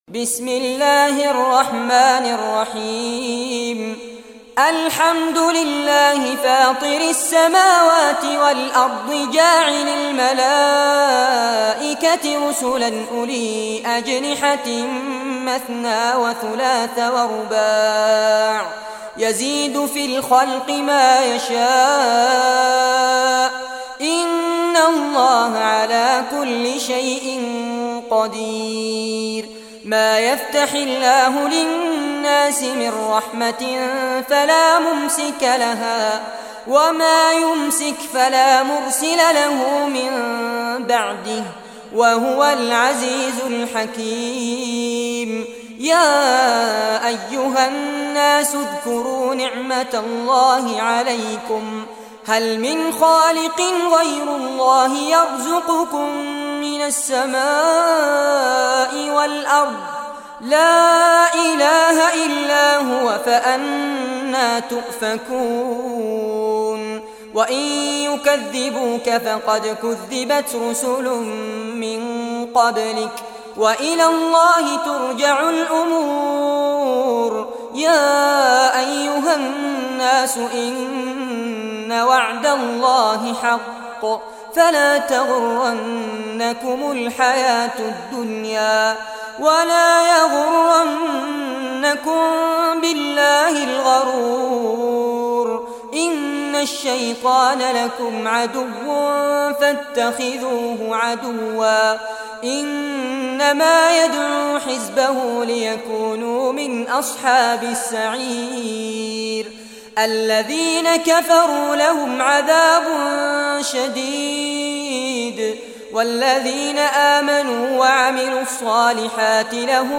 Surah Fatir Recitation